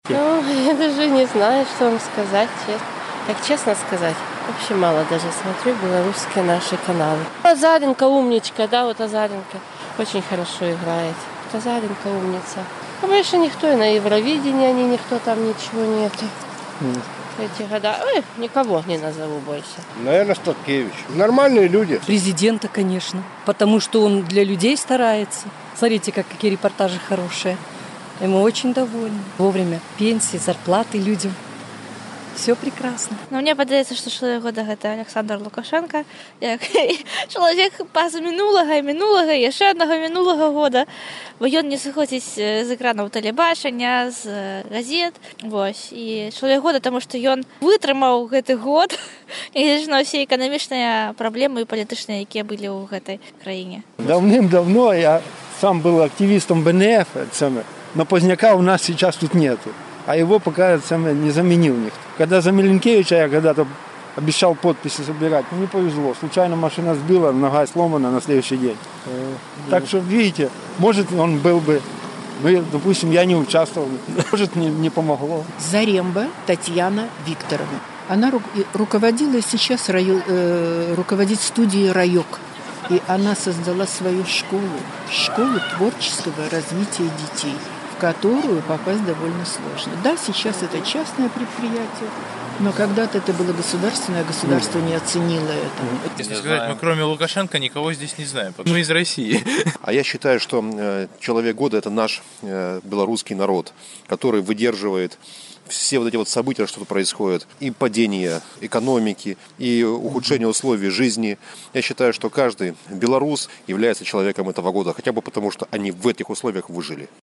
Каго вы лічыце чалавекам 2012 году ў Беларусі? Адказваюць мінакі ў Берасьці